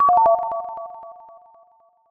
snd_ominous.wav